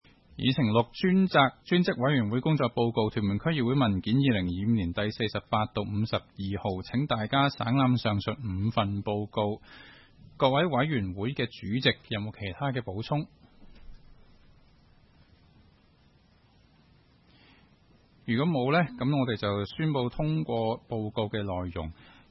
区议会大会的录音记录
屯门区议会会议室